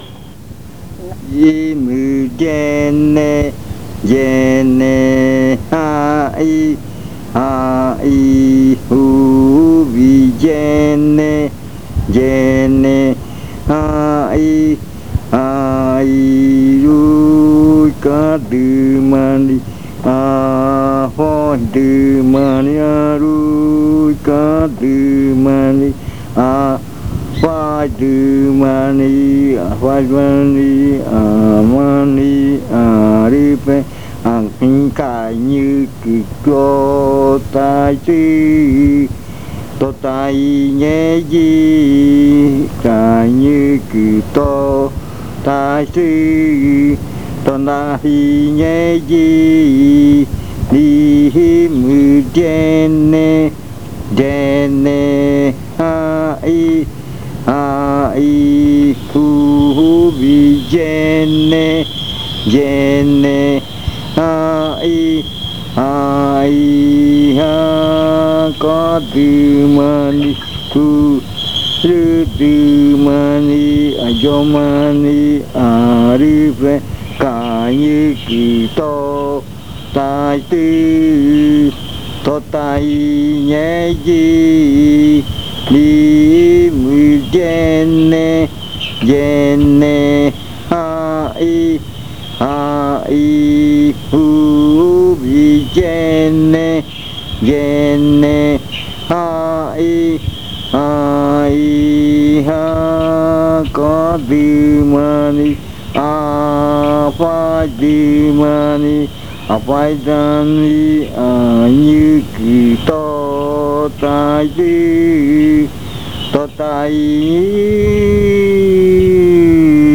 Leticia, Amazonas
Canción de arrimada (netaja rua) 3:00-4:00 PM.
Canto con adivinanza.
Chant with a riddle.